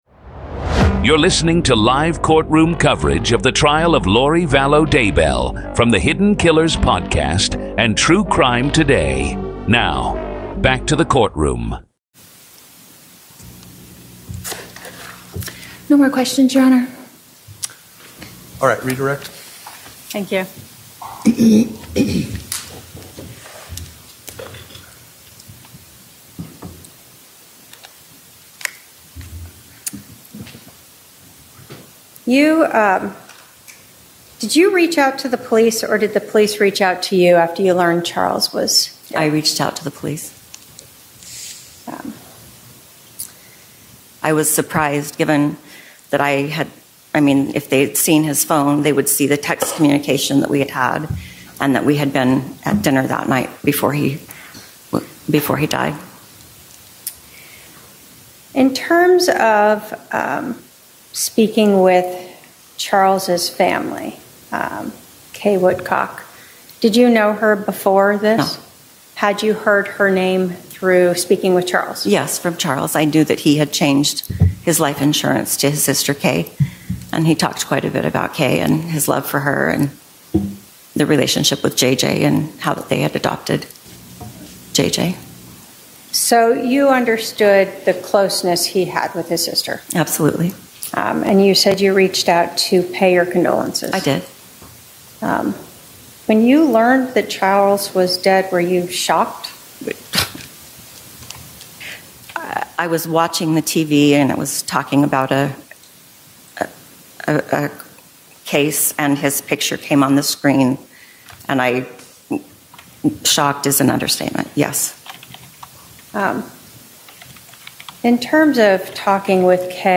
Full Courtroom Coverage